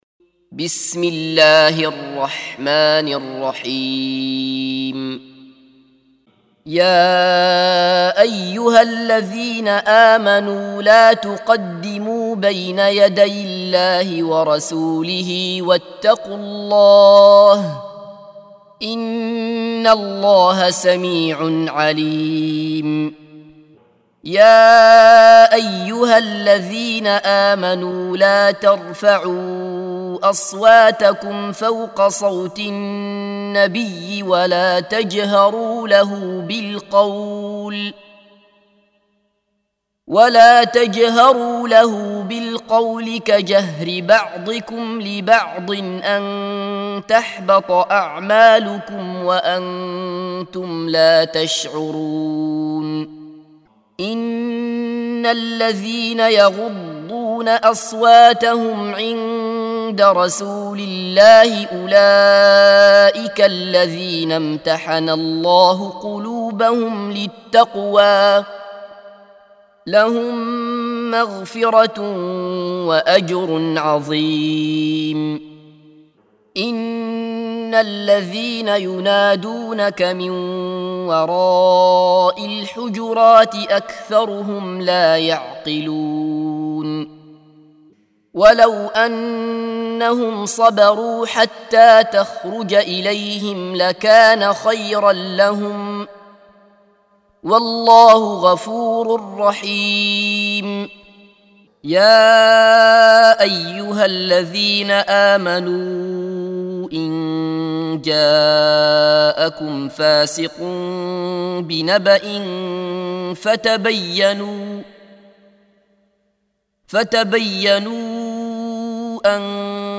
سُورَةُ الحِجۡرِات بصوت الشيخ عبدالله بصفر